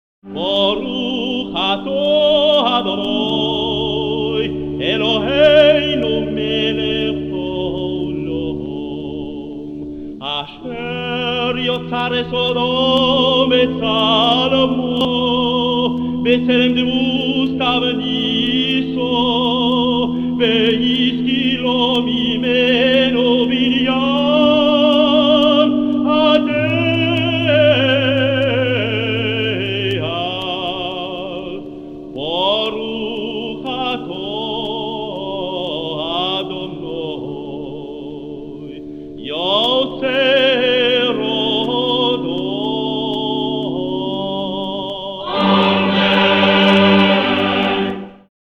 4e bénédiction (rite ashkenaze
accompagné à l’orgue